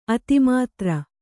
♪ ati mātra